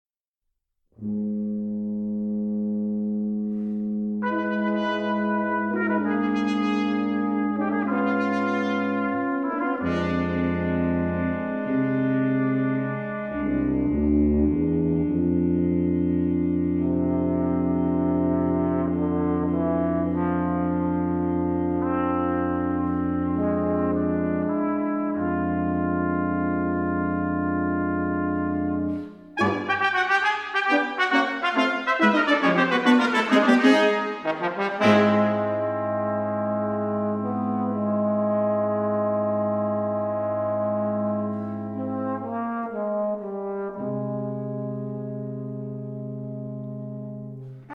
For Brass Quintet